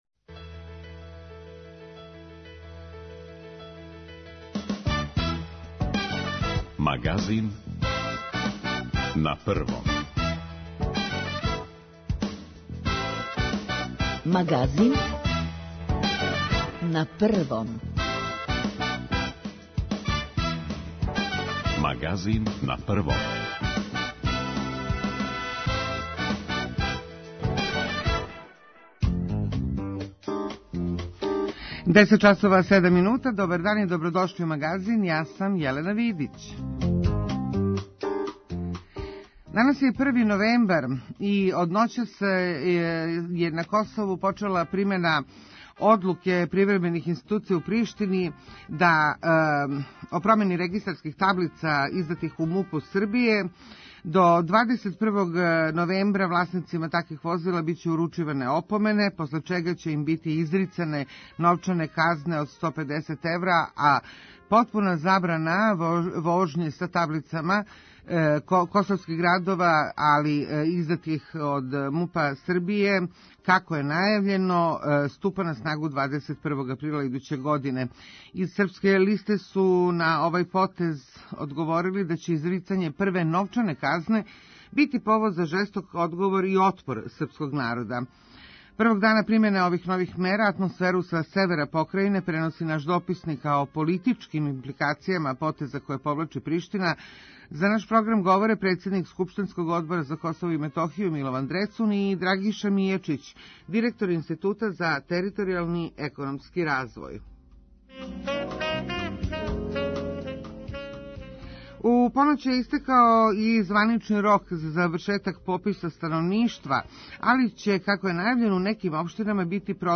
Првог дана примене нових мера атмосферу са севера покрајине преноси наш дописник а о политичким импликацијама потеза које повлачи Приштина говори председник скупштинског одбора за Косово и Метохију Милован Дрецун.